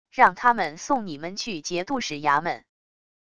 让他们送你们去节度使衙门wav音频生成系统WAV Audio Player